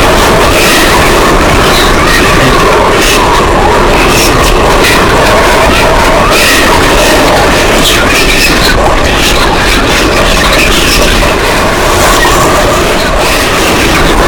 Whispers2.ogg